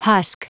Prononciation du mot husk en anglais (fichier audio)
husk.wav